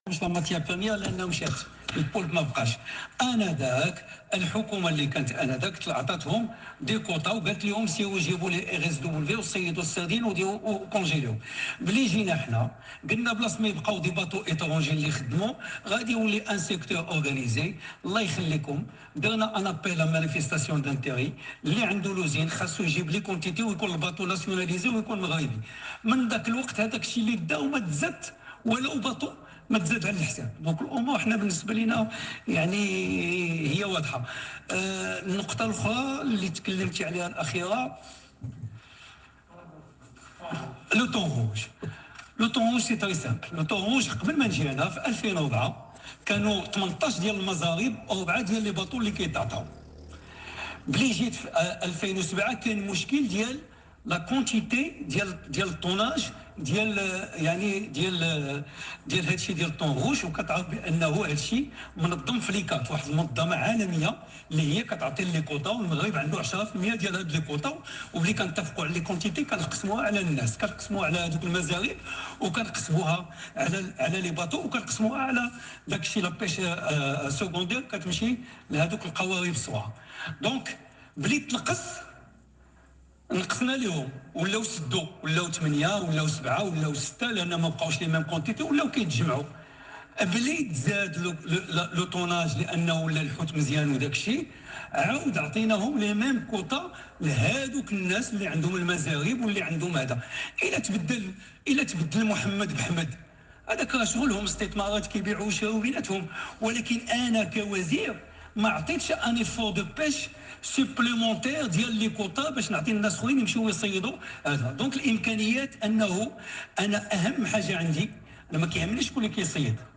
قال وزير الفلاحة والصيد البحري عزيز أخنوش، خلال جلسة الأسئلة الشفوية بمجلس النواب اليوم الإثنين ردا على سؤال برلماني وجه له بخصوص حصص التونة الحمراء التي باتت تخصص البعض كما يبدو، (قال) أنه قبل مجيئه سنة 2004، كانت هناك 18 مزربة و 4 بواخر لصيد التونة.
مداخلة الوزير هنا: